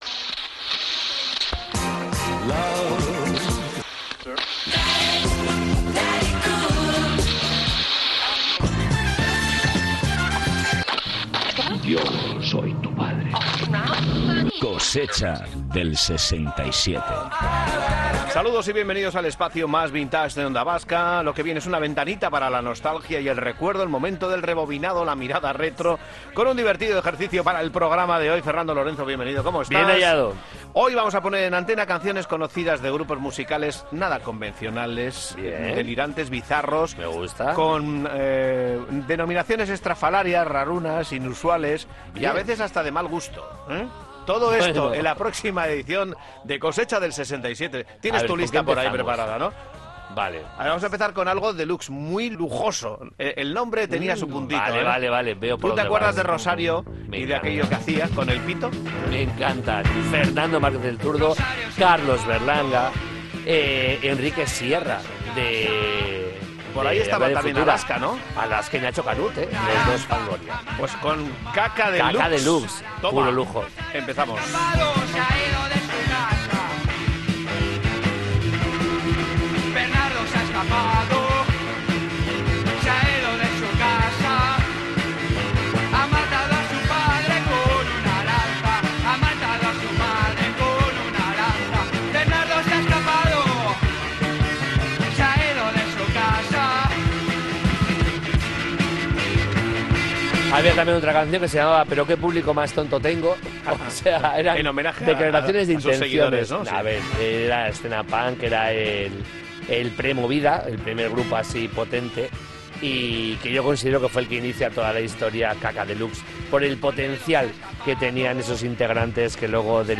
Espacio conectado a la nostalgia a través del humor y la música.